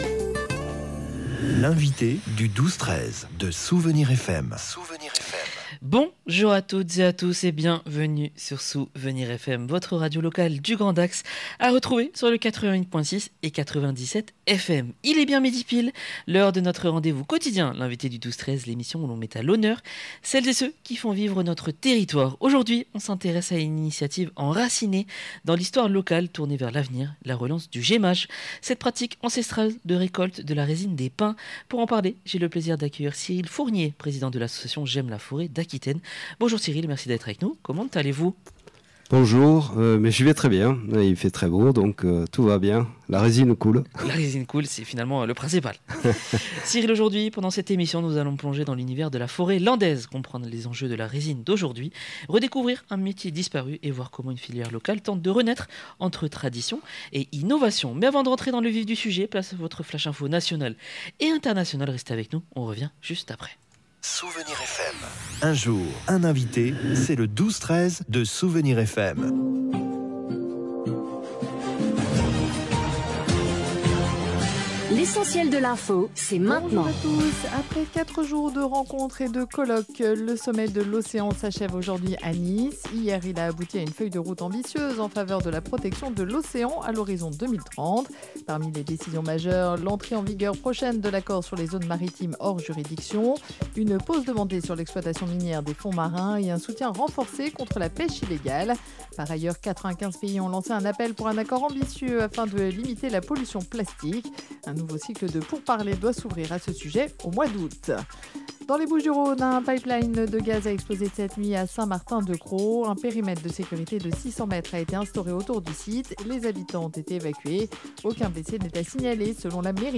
Ce midi, SOUVENIRS FM recevait l’association Gemme la Forêt d’Aquitaine